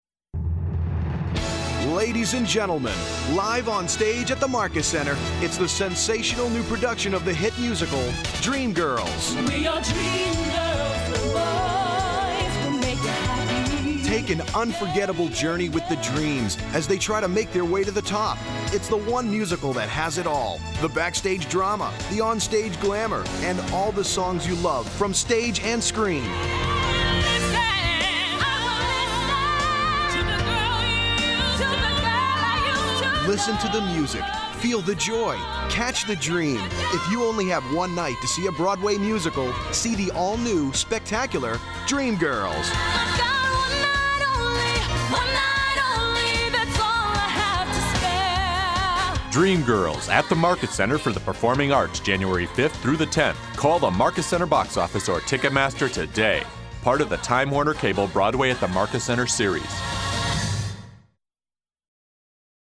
Dreamgirls Radio Commercial